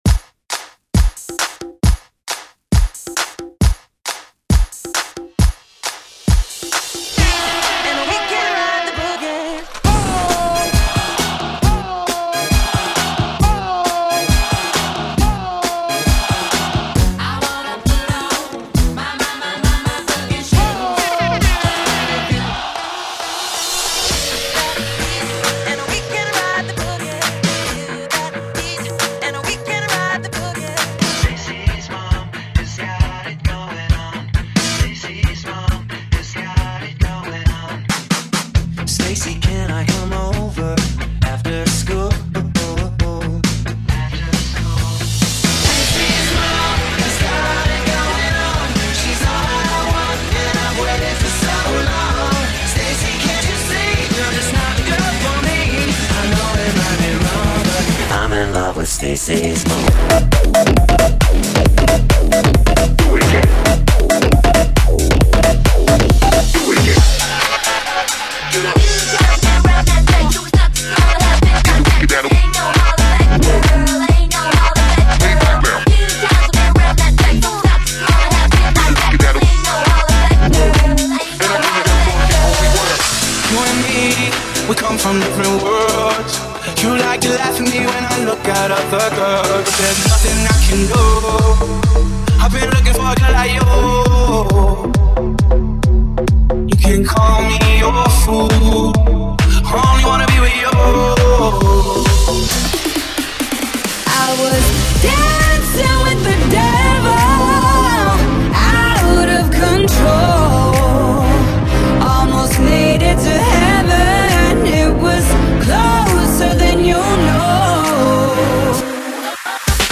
BPM: 135 [60:00]
FORMAT: 32COUNT
Club, House & some Funky Beats.